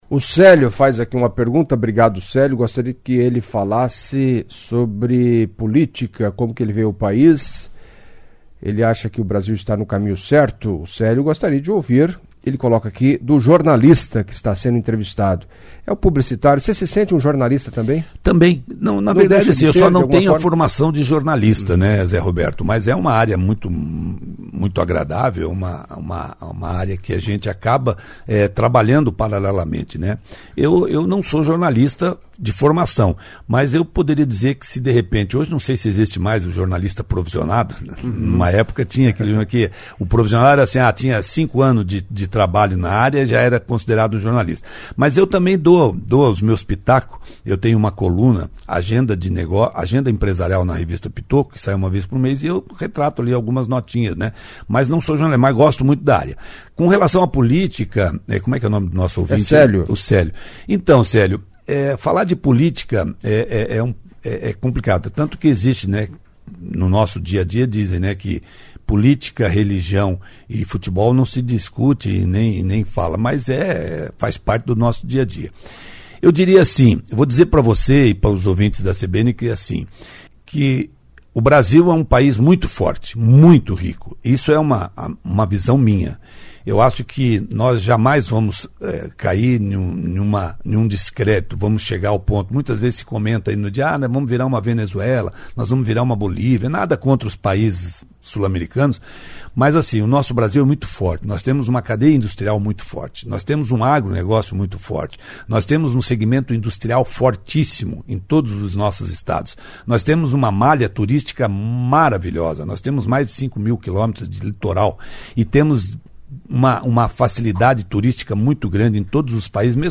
Em entreviasta à CBN Cascavel